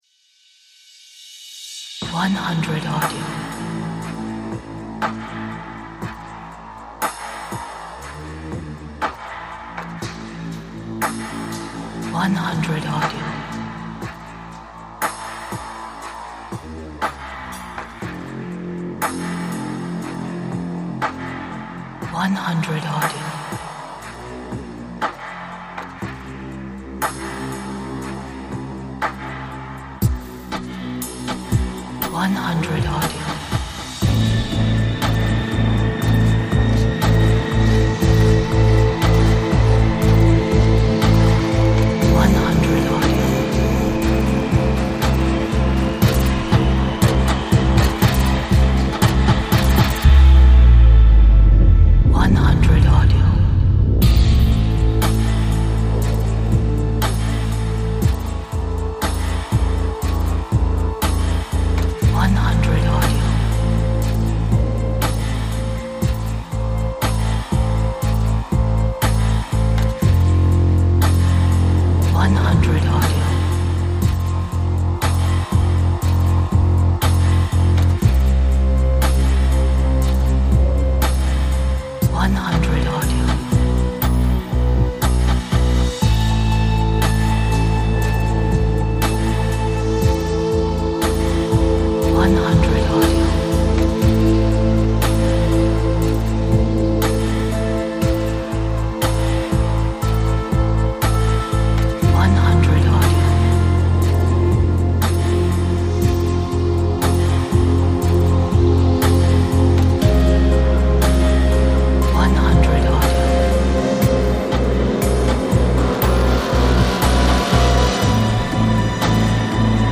Gloomy electronics for sounding the game or block buster.